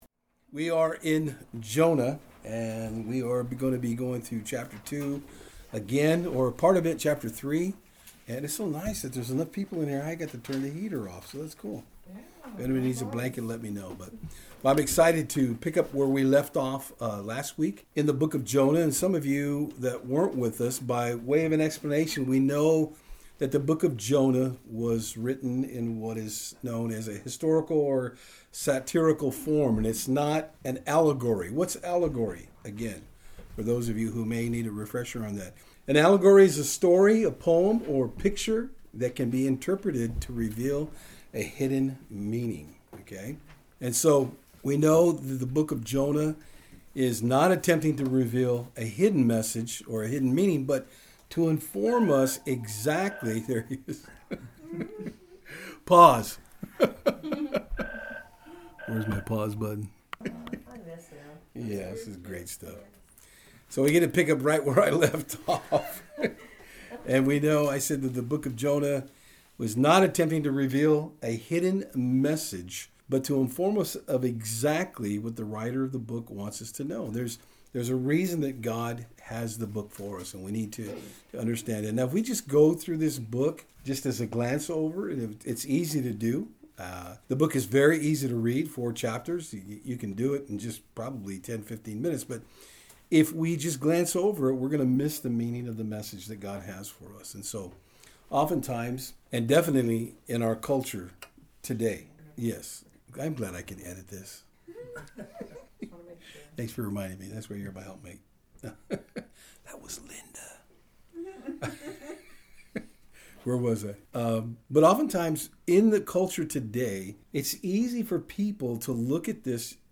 A real treat to get a call during the study from our folks in Idaho who are following along with us through the book of Jonah. Tonight we will be looking at how God never relents in HIs desire to get Jonah back on track to deliver the message He had given him for the Ninevites.
Service Type: Saturdays on Fort Hill